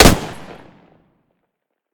defender-shot-2.ogg